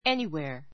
anywhere 中 A2 éni(h)weə r エ ニ (ホ) ウェア 副詞 ❶ 疑問文・条件文で どこかに[へ] Did you go anywhere yesterday?